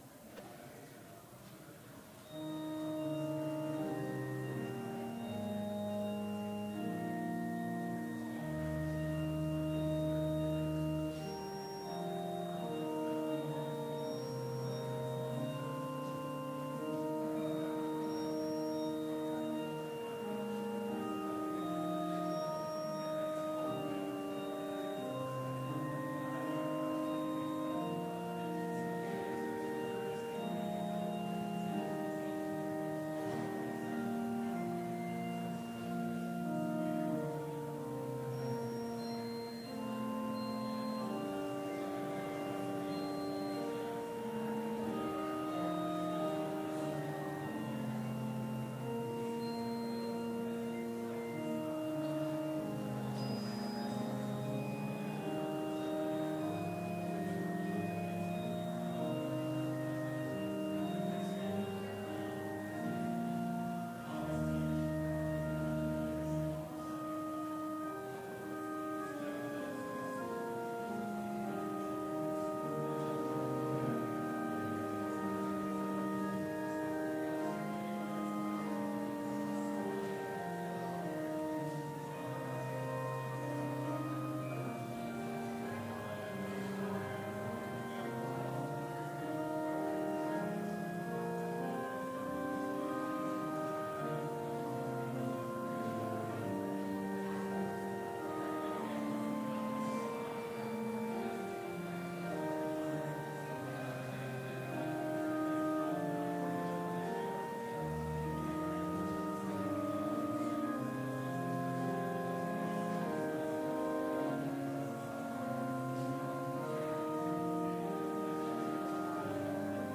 Chapel service on April 4, 2019, at Bethany Chapel in Mankato, MN,
Complete service audio for Chapel - April 4, 2019